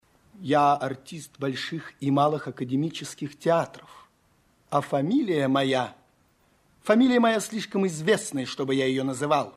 Звуки цитат из фильма
В подборке — короткие, но узнаваемые фразы из популярных кинокартин, подходящие для установки на звонок, использования в мемах или видеороликах.